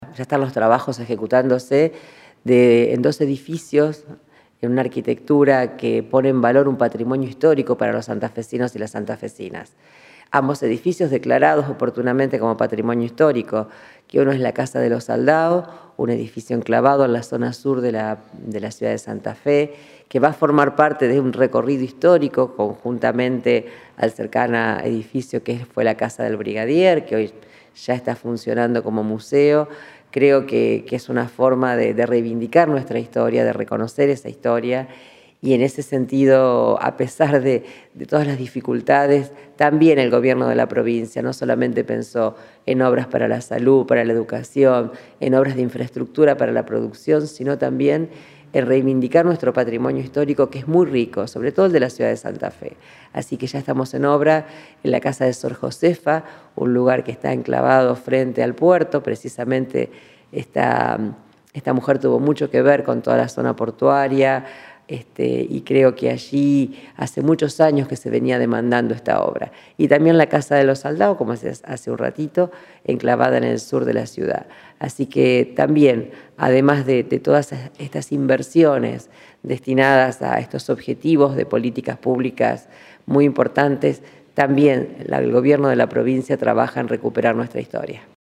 Silvina Frana, ministra de Infraestructura